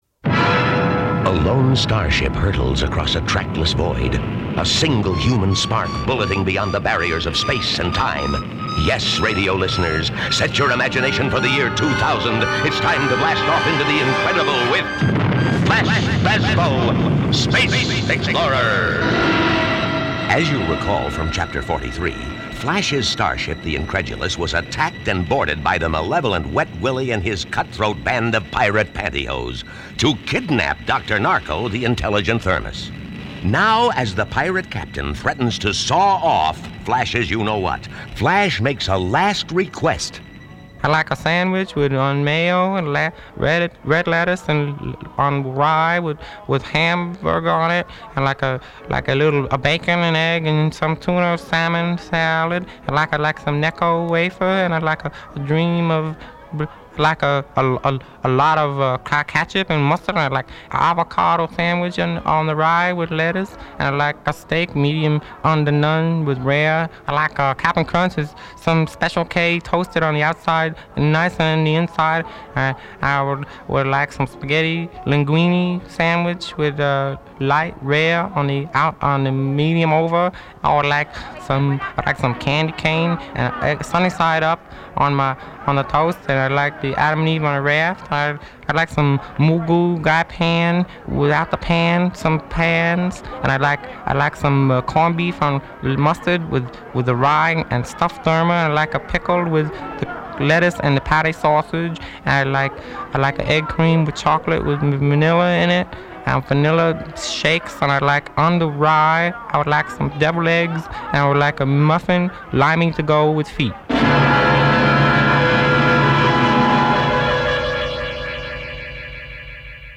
Here's an example of Guest's early work: Flash Bazbo, Space Explorer, one of the offbeat "character sketches" that he created for the NLRH: